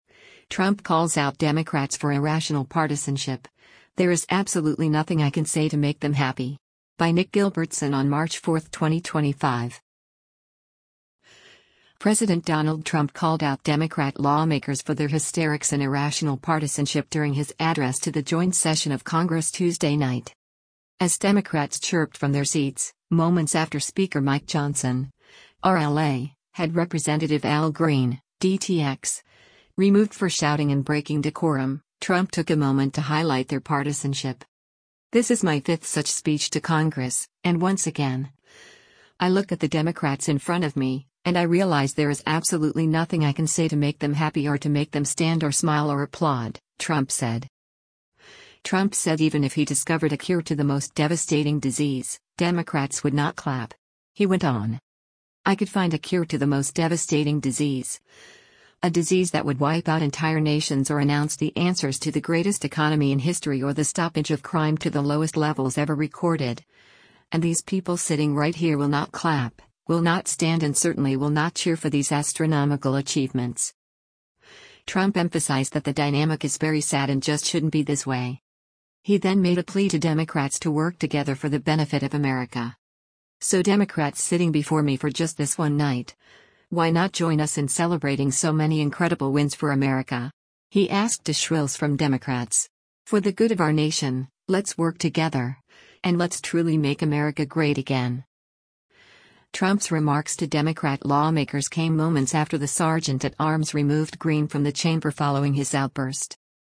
President Donald Trump called out Democrat lawmakers for their hysterics and irrational partisanship during his address to the joint session of Congress Tuesday night.
As Democrats chirped from their seats, moments after Speaker Mike Johnson (R-LA) had Rep. Al Green (D-TX) removed for shouting and breaking decorum, Trump took a moment to highlight their partisanship.
“So Democrats sitting before me for just this one night, why not join us in celebrating so many incredible wins for America?” he asked to shrills from Democrats.